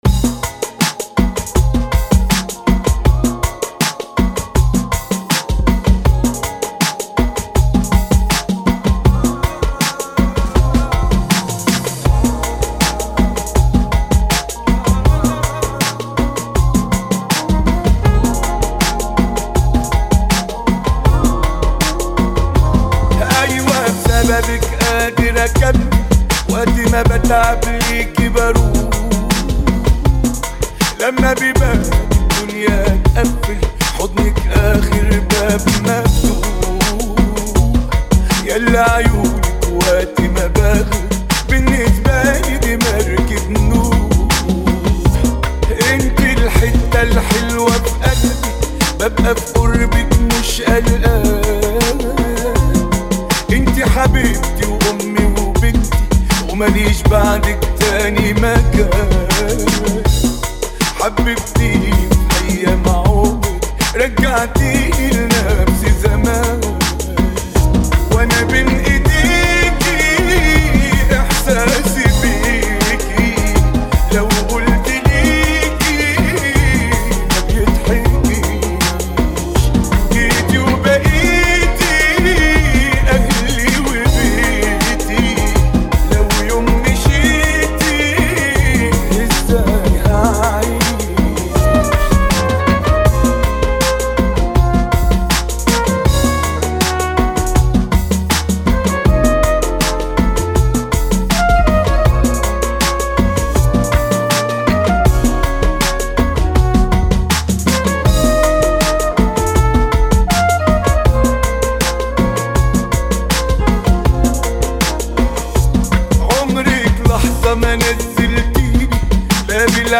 [ 80 bpm ] 2023